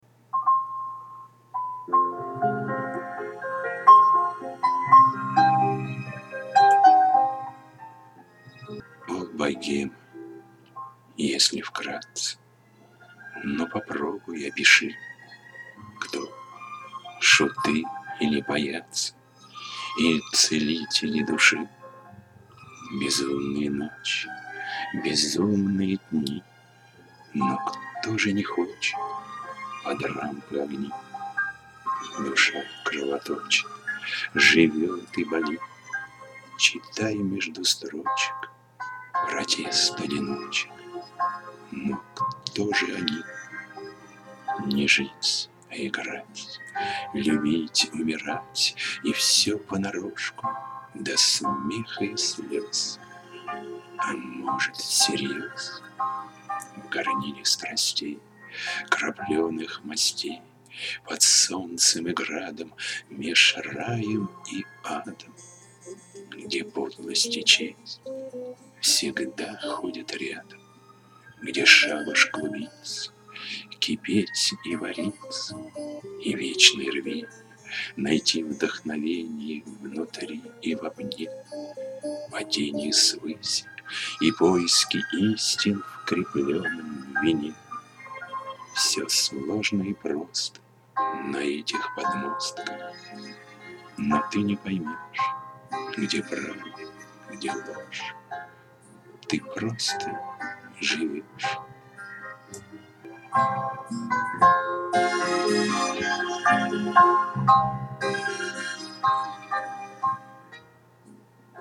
О!Звучание уже луЧЧе))))